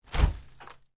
На этой странице собраны разнообразные звуки сундуков: скрип дерева, стук металлических застежек, глухой гул пустого пространства внутри.
Раскрытие крышки сундука